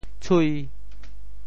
潮州发音 潮州 cui5
tshui5.mp3